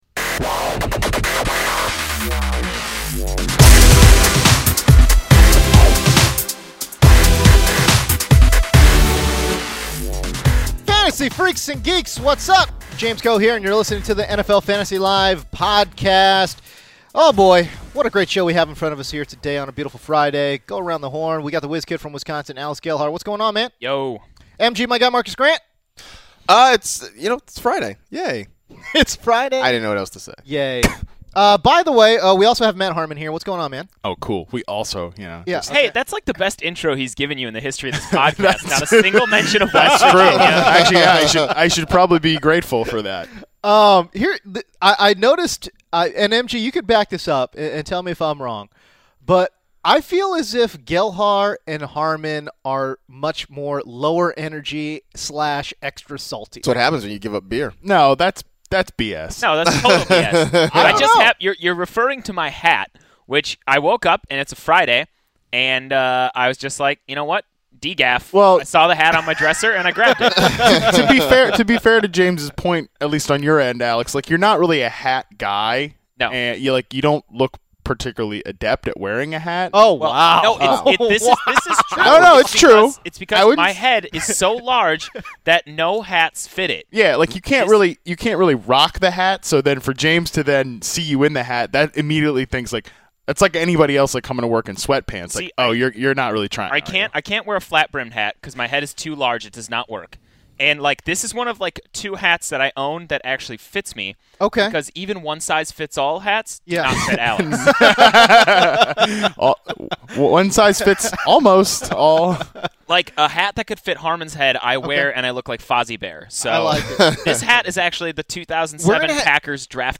After that, they look at some surprising fantasy points against totals from the first four weeks, preview every Week 5 game, and serve up some deep sleepers with four teams on byes. As always, they close out the show with a round of Daily Daps.